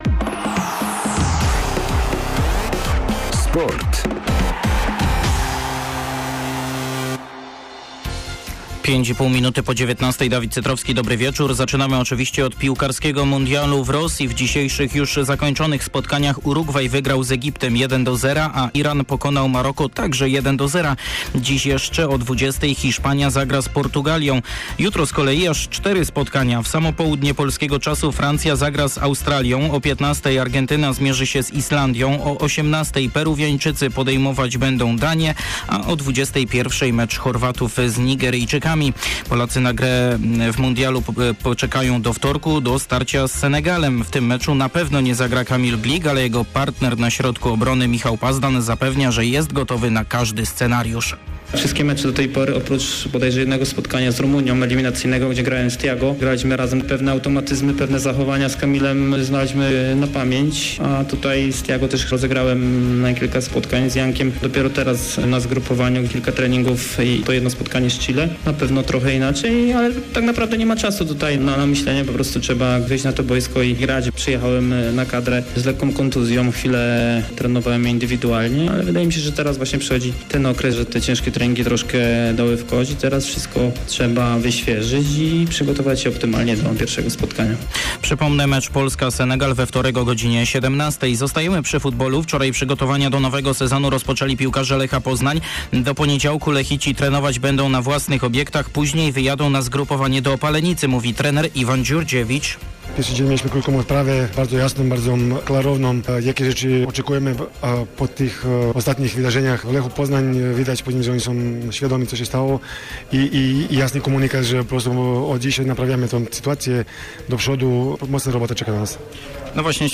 15.06 serwis sportowy godz. 19:05